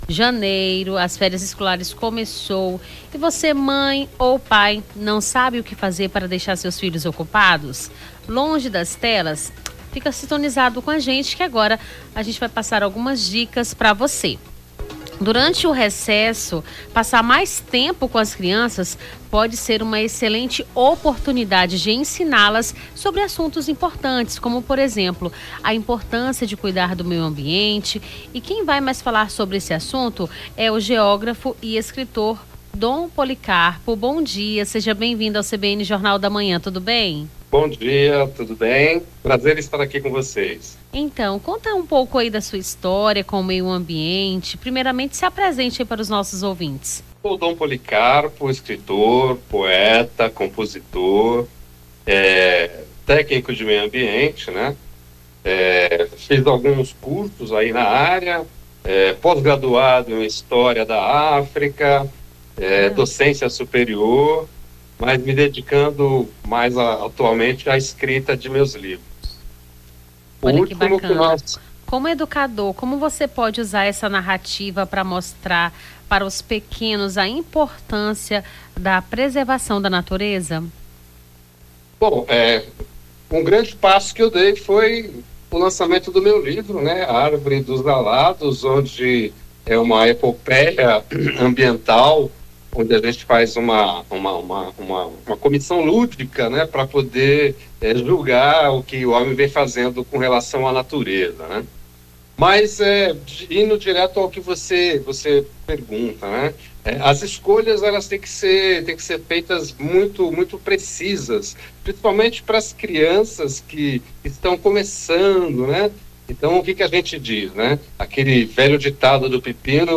Nome do Artista - CENSURA - ENTREVISTA FÉRIAS ESCOLARES (06-01-25).mp3